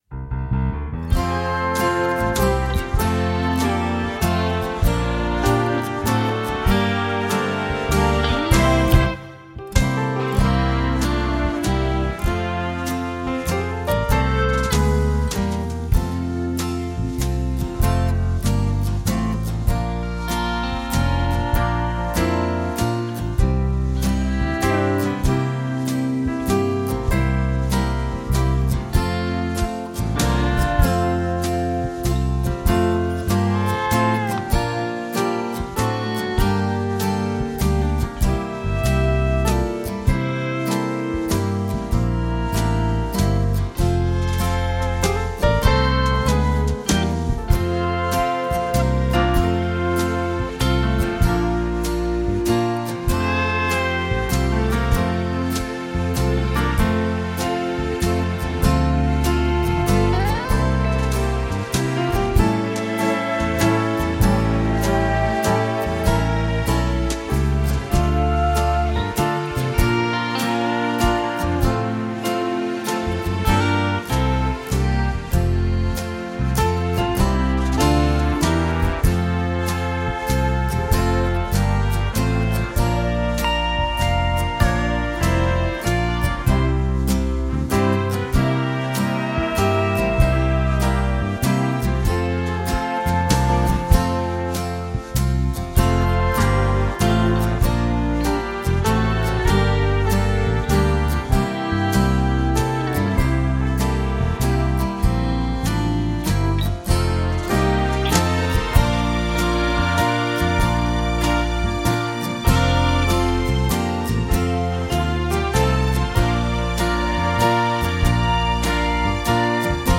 Without singing: